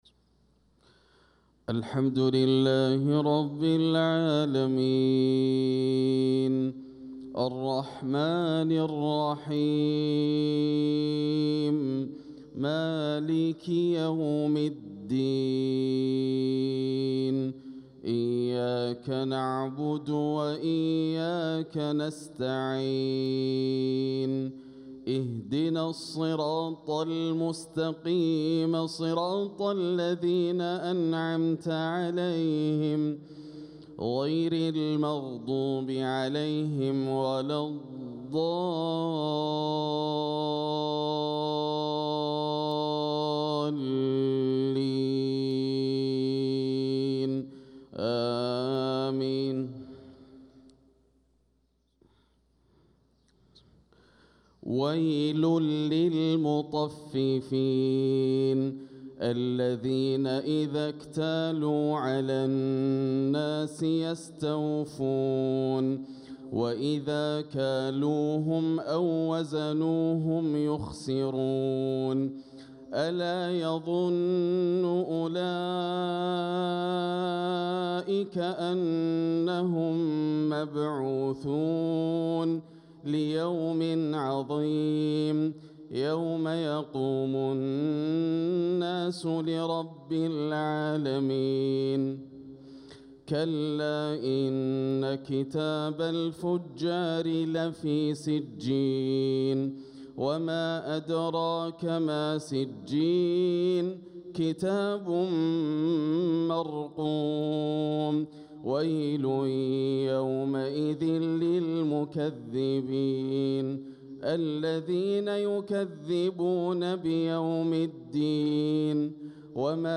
صلاة الفجر للقارئ ياسر الدوسري 28 ربيع الأول 1446 هـ
تِلَاوَات الْحَرَمَيْن .